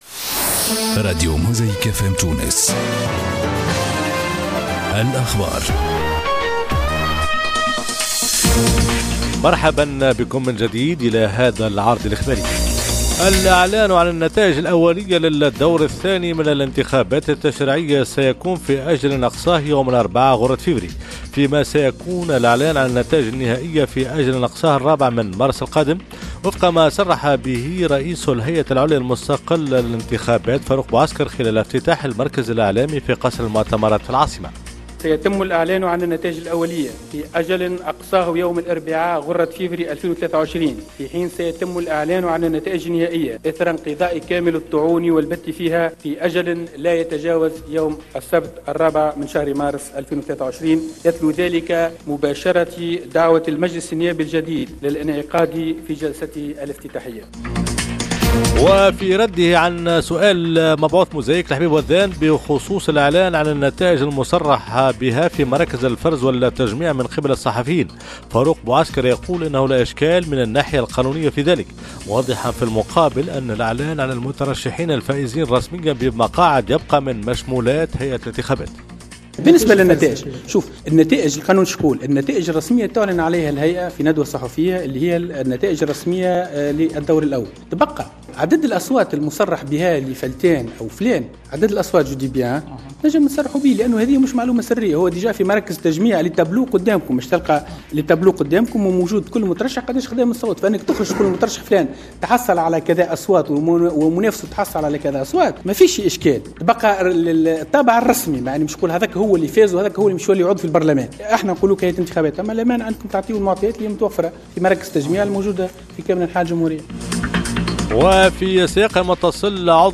نشرات أخبار جانفي 2023